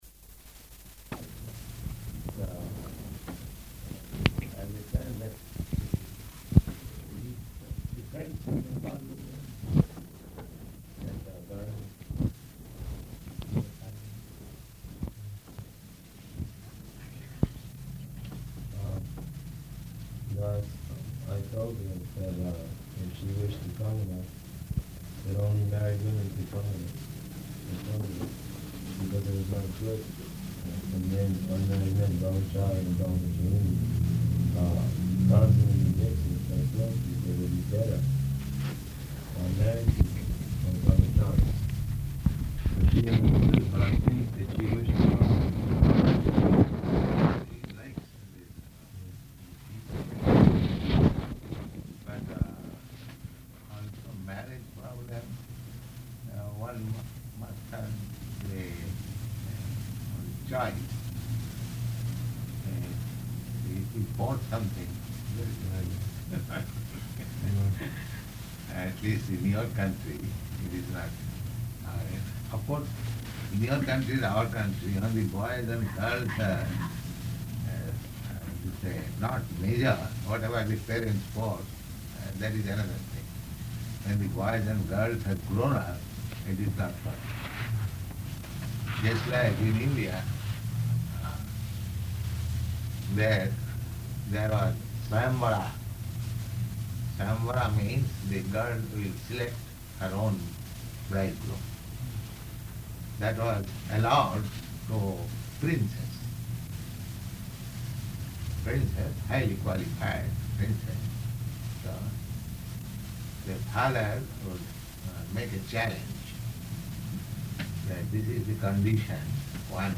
Room Conversation about Marriage
Type: Conversation
Location: Seattle